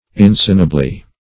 insanably - definition of insanably - synonyms, pronunciation, spelling from Free Dictionary Search Result for " insanably" : The Collaborative International Dictionary of English v.0.48: Insanably \In*san"a*bly\, adv. In an incurable manner.
insanably.mp3